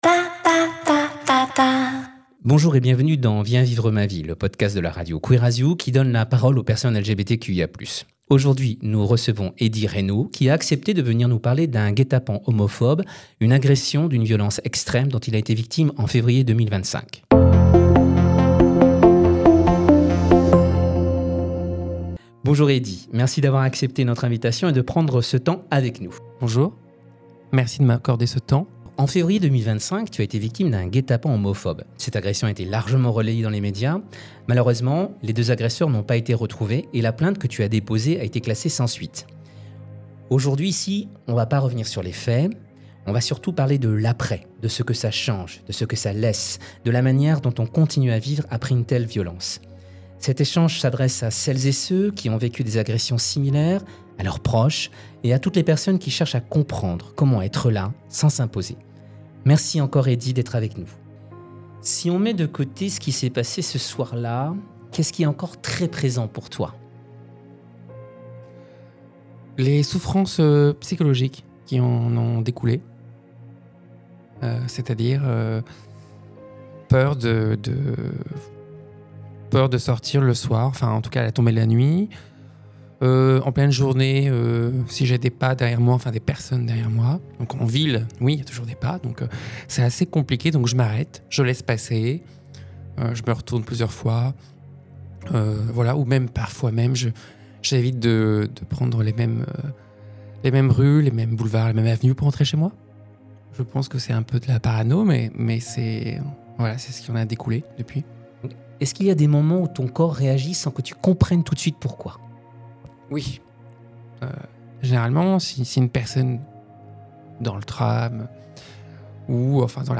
Bienvenue dans Viens vivre ma vie, le podcast de LA Radio Queer as You, où on donne la parole à des personnes LGBTQIA+ qui partagent leurs expériences et les défis qu’elles rencontrent au quotidien, à travers des interviews ou des témoignages.